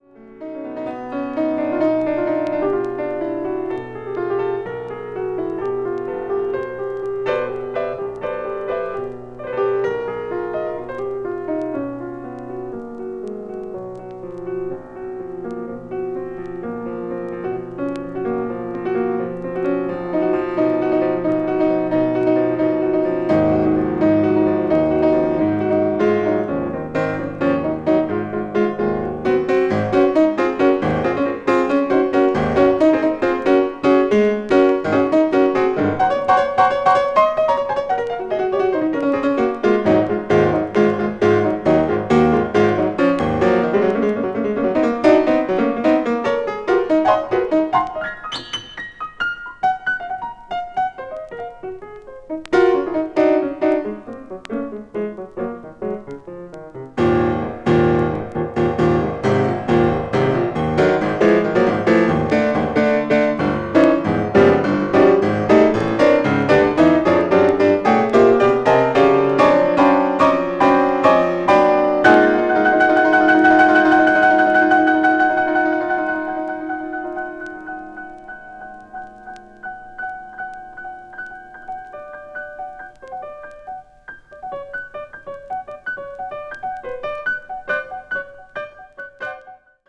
media : VG+/VG+(わずかなチリノイズ/軽いチリノイズが入る箇所あり)
avant-jazz   free improvisation   free jazz   piano solo